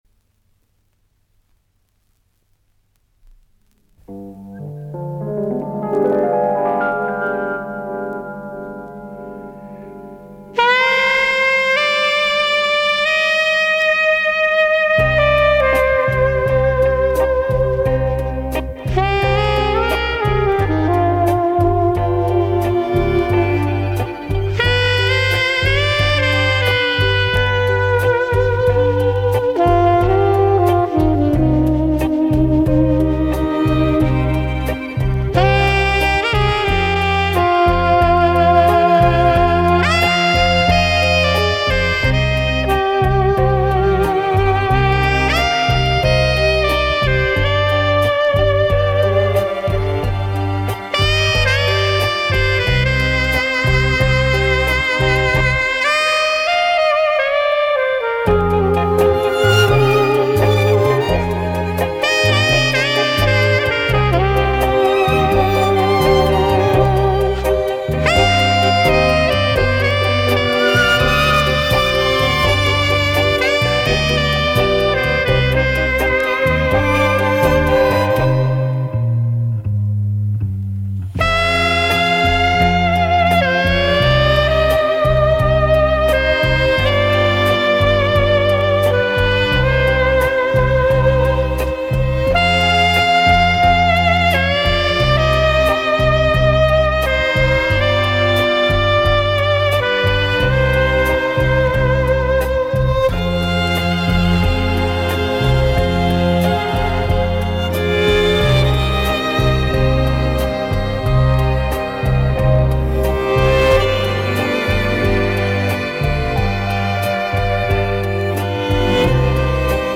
Очень выразительный японский саксофон